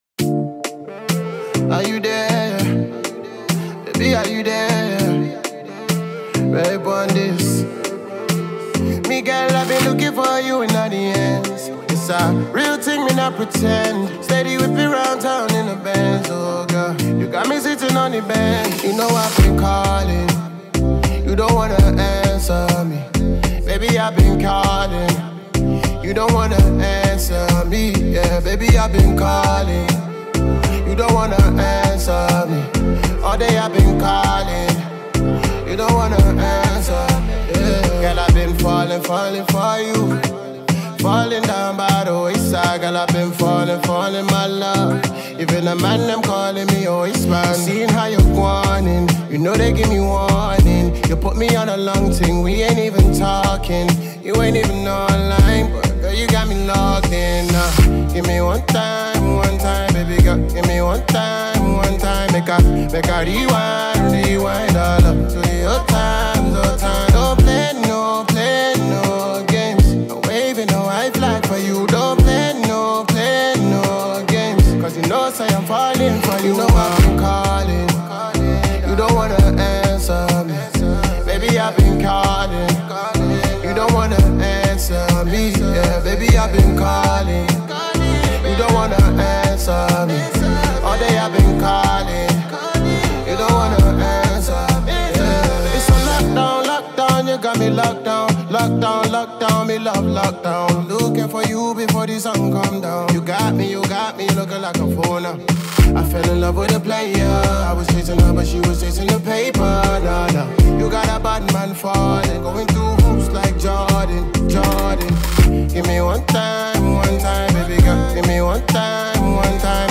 wavy single for the summer